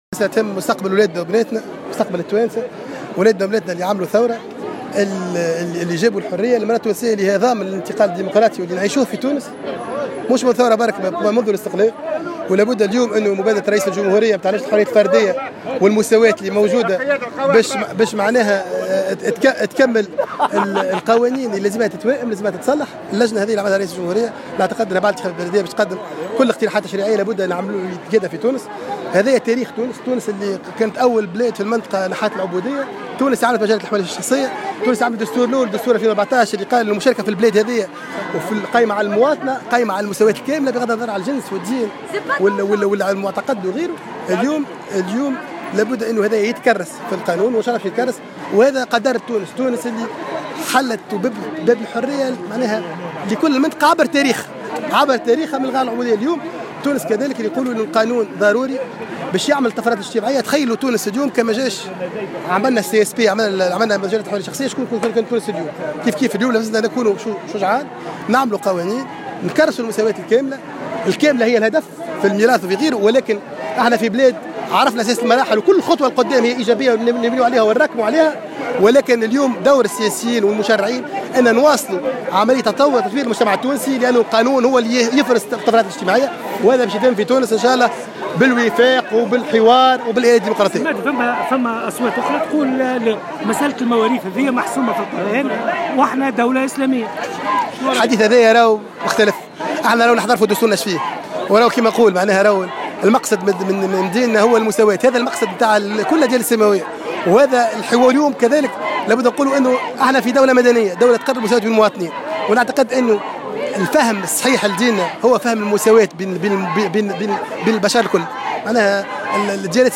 شارك اليوم السبت وزير العلاقة مع الهيئات الدستورية والمجتمع المدني وحقوق الإنسان، مهدي بن غربية، في المسيرة الوطنية للمطالبة بالمساواة في الميراث.